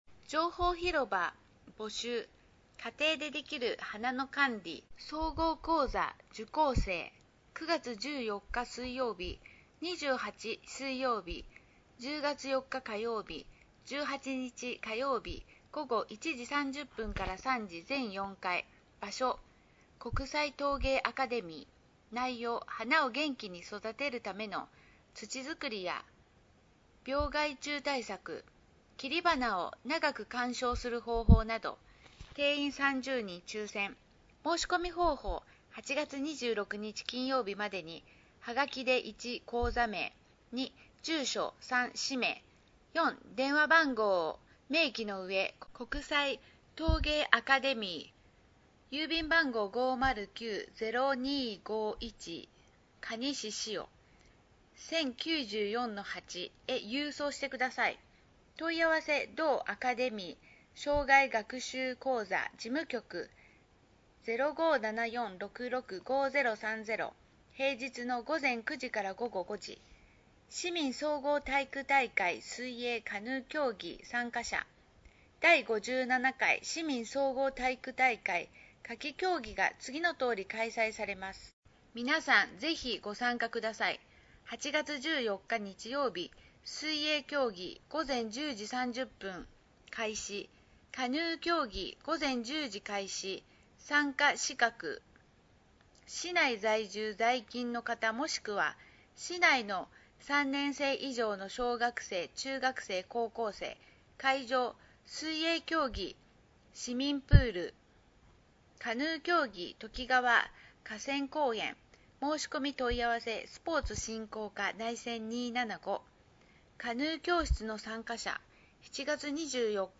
音声欄に表示があるものは、「声の広報」として、音声にてお聴きになれます。